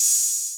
Open Hats
RAZ Open Hat 4 [ Metro ].wav